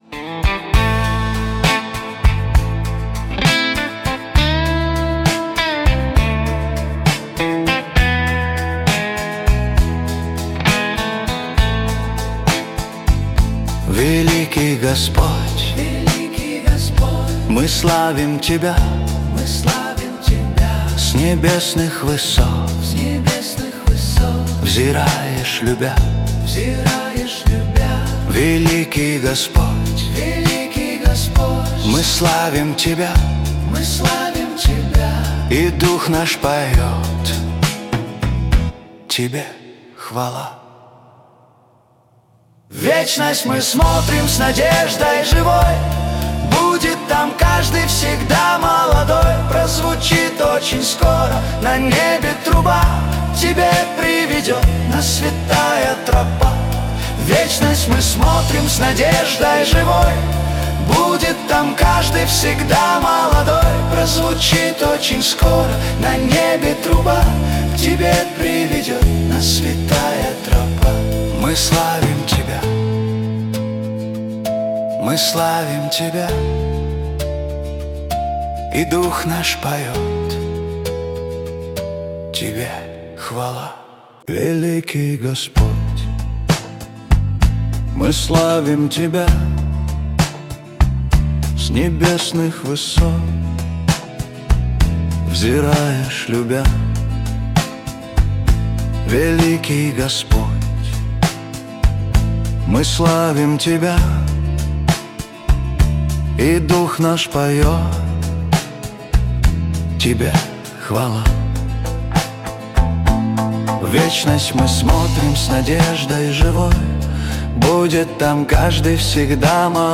песня ai
153 просмотра 1179 прослушиваний 49 скачиваний BPM: 67